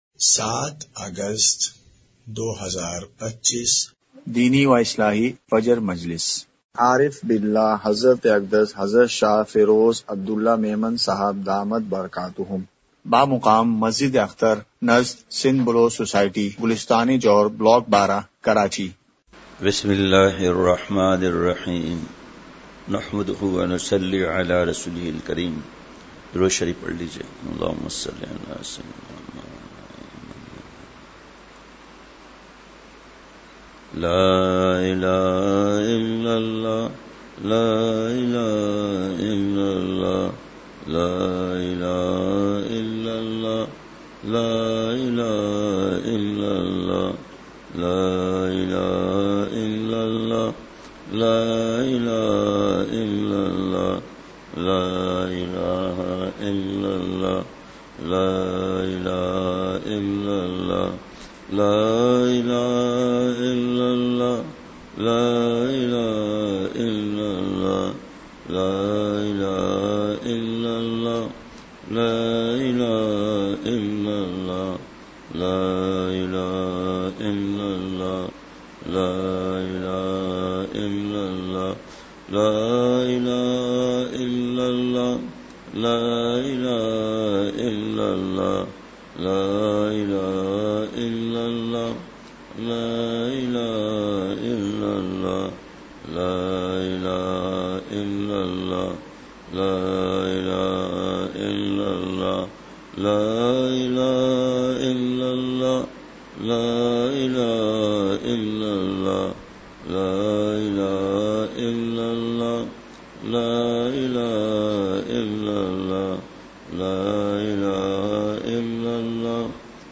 مقام:مسجد اختر نزد سندھ بلوچ سوسائٹی گلستانِ جوہر کراچی
مجلسِ ذکر:کلمہ طیّبہ۔۔۔!!